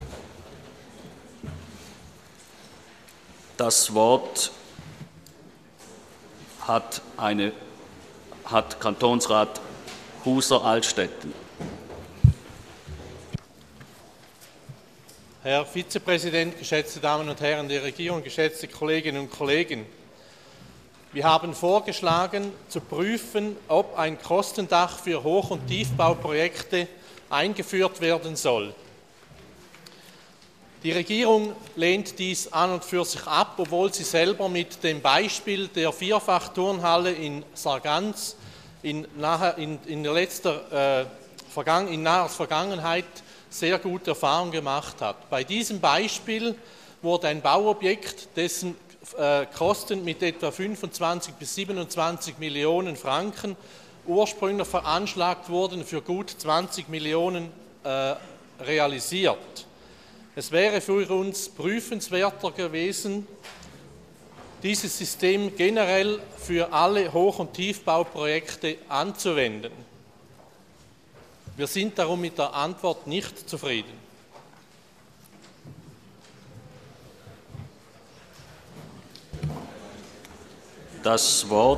26.2.2013Wortmeldung
Session des Kantonsrates vom 25. bis 27. Februar 2013